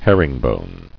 [her·ring·bone]